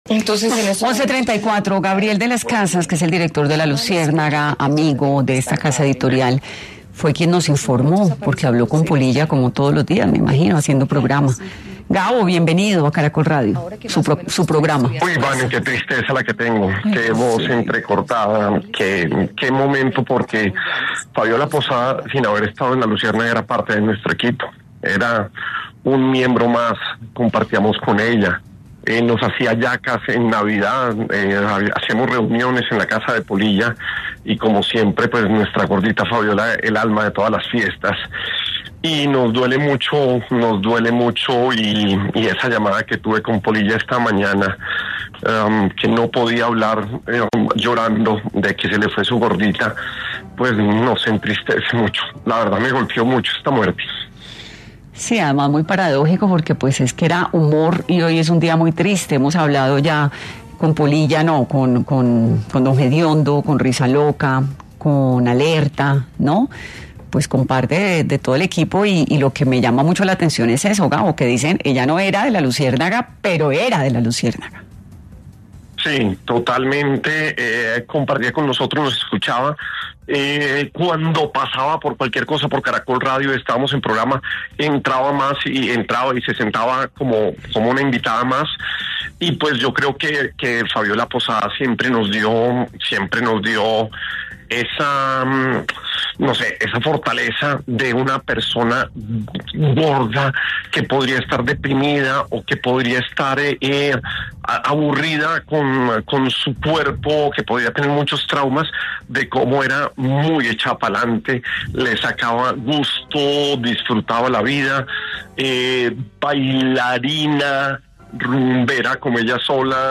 Entrevista de Gabriel de las Casas sobre la Gorda Fabiola